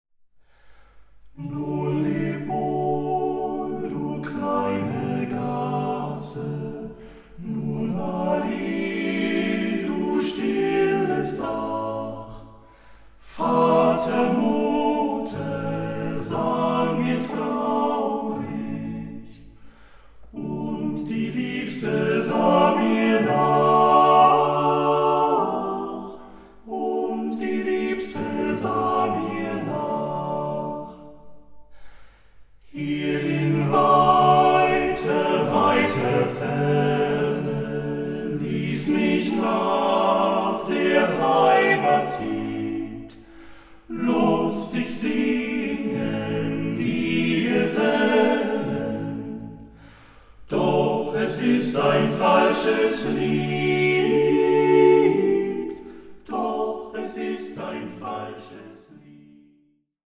award-winning vocal ensemble
the eight singers revive an entire folk culture!